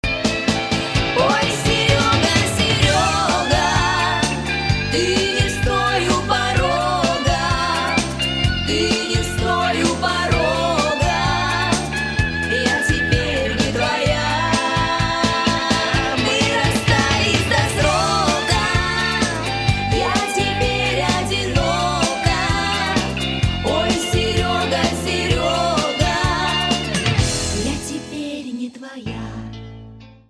• Качество: 192, Stereo
поп
90-е